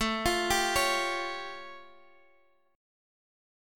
A+7 chord